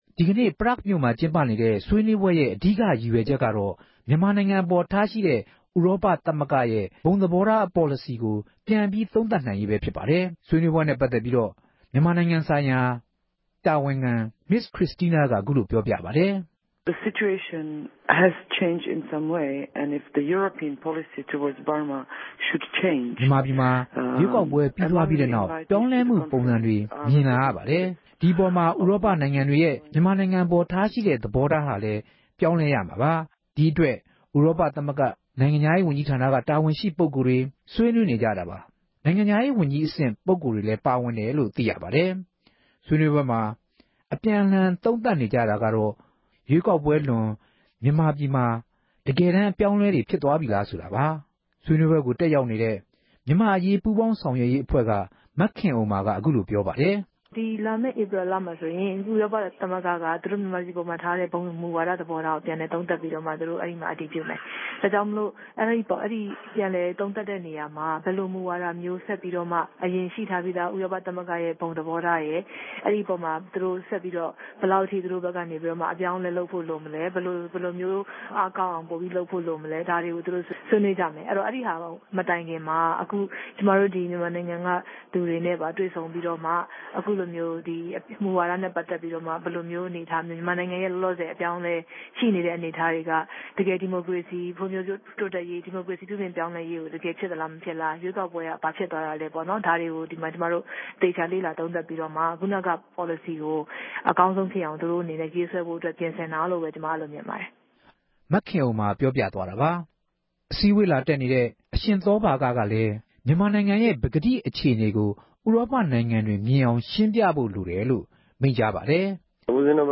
Prague မြို့က မြန်မာ့အရေး ဆောင်ရွက်နေသူတွေကို မေးမြန်းပြီး စုစည်း တင်ပြထားပါတယ်။
စုစည်းတင်ပြချက်